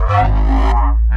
Bass 1 Shots (98).wav